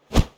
Close Combat Swing Sound 46.wav